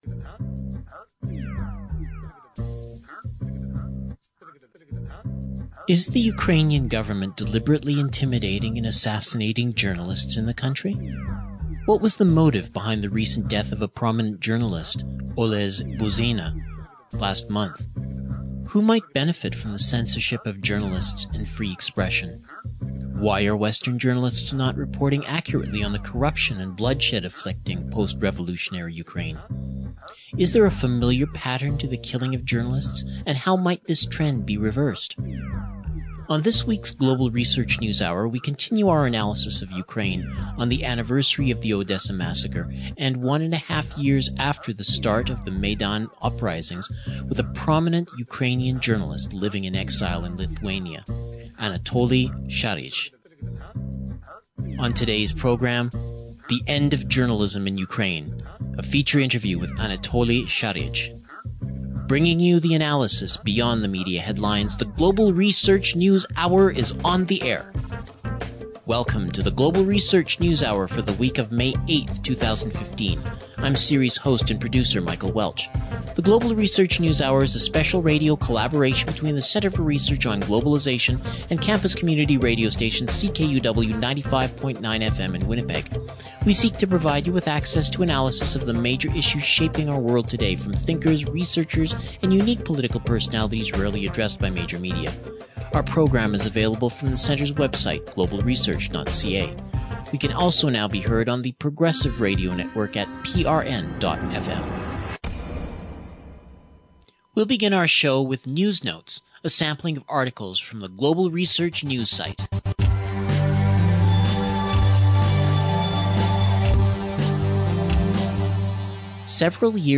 The End of Journalism in Ukraine. A Feature interview with Anatoly Sharij